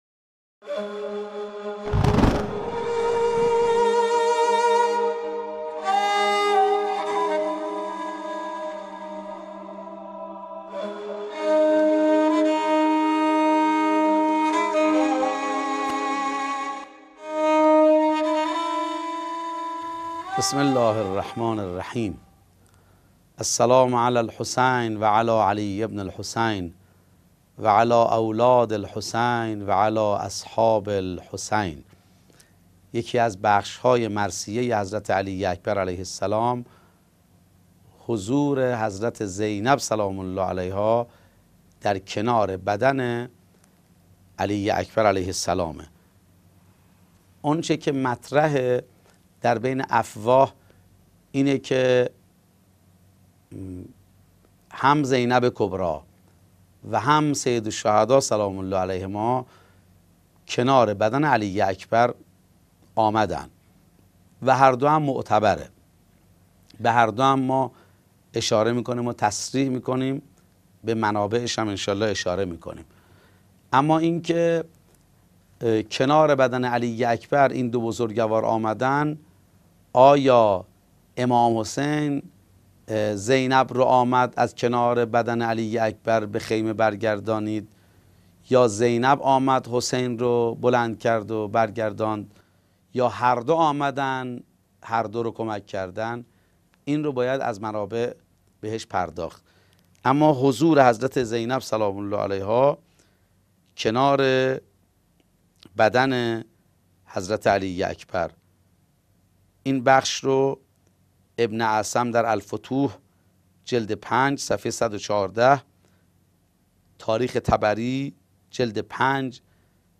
سلسله گفتارهایی پیرامون تبارشناسی عاشورا